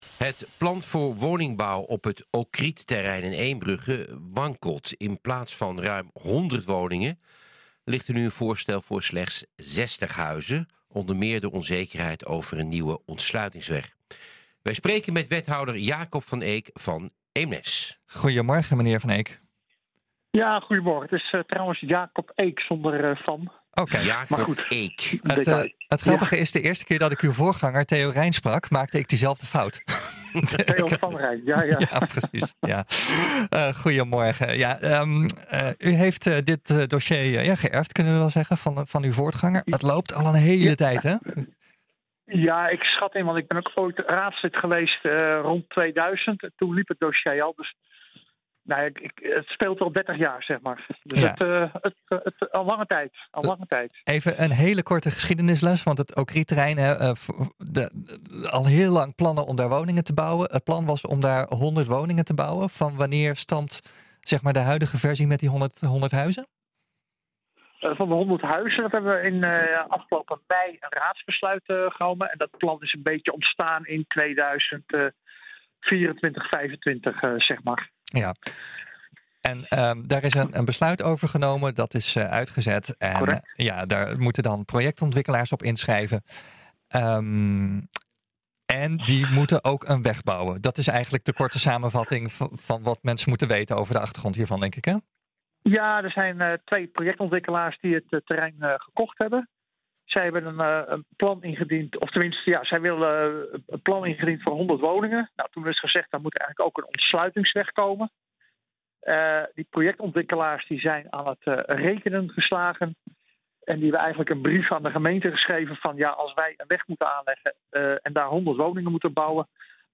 In plaats van ruim honderd woningen ligt er nu een voorstel voor slechts zestig huizen, onder meer door onzekerheid over een nieuwe ontsluitingsweg. Wij spreken met wethouder Jacob Eek van Eemnes.